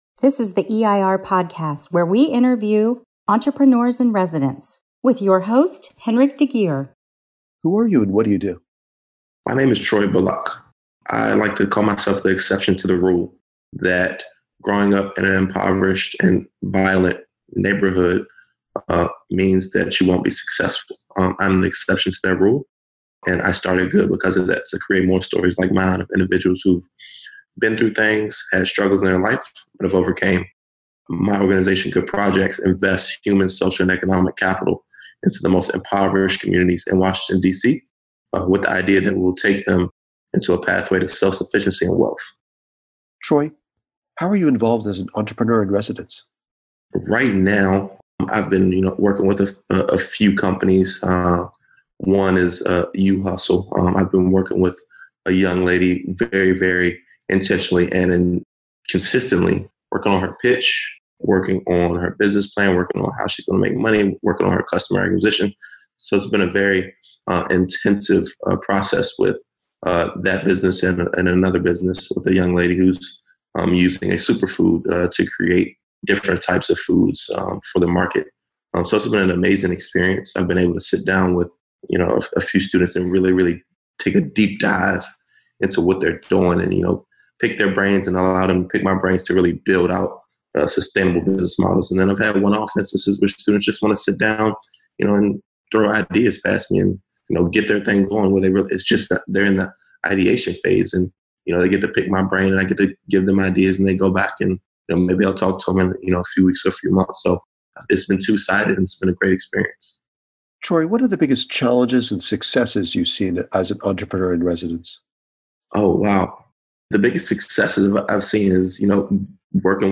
Here is an audio interview with Entrepreneur in Residence